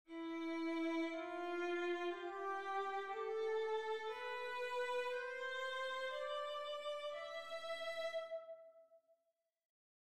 Next up is the scale from E to E (you’re either getting the hang of this by now or have fallen asleep); it produces the pattern STTTSTT and is called the Phrygian mode.
Phrygian
phrygian.mp3